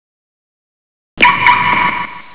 Cŕŕn Take dvojité zatukanie 0:02